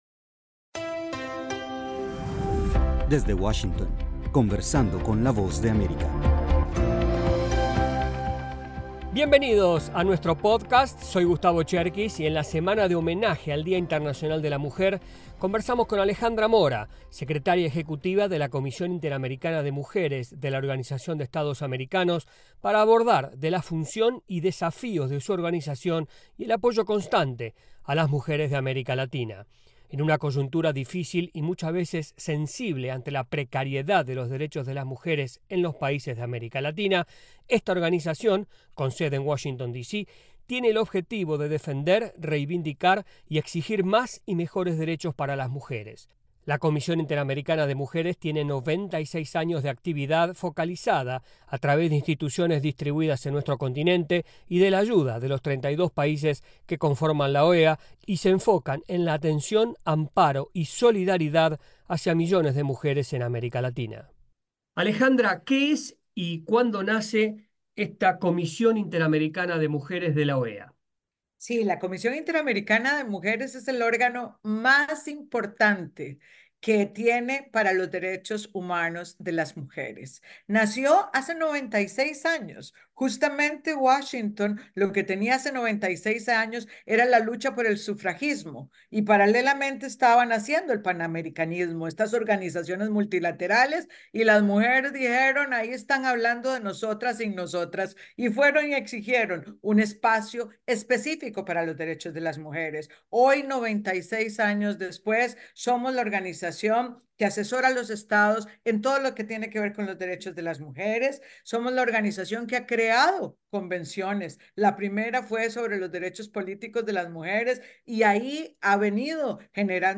En esta emisión de Conversando con la Voz de América entrevistamos a Alejandra Mora, secretaria ejecutiva de la Comisión Interamericana de Mujeres de la Organización de los Estados Americanos OEA.